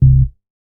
MoogShotLivd 013.WAV